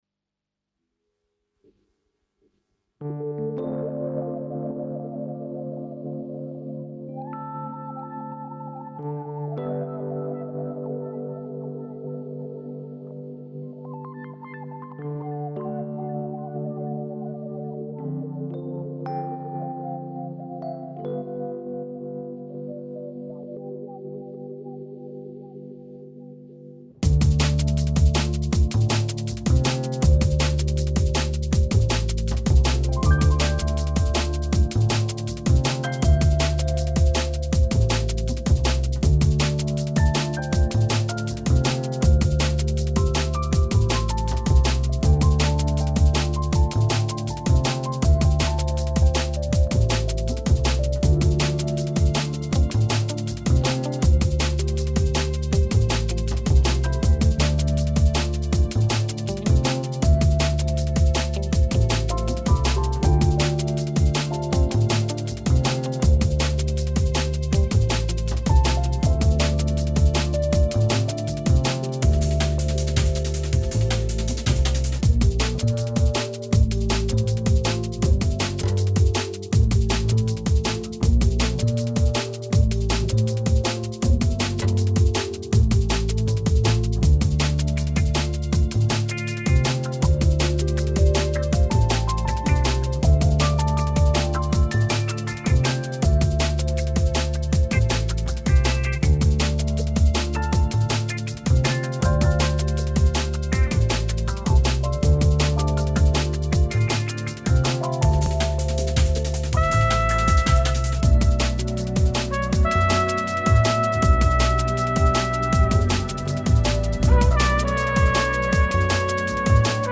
JAZZ LOUNCE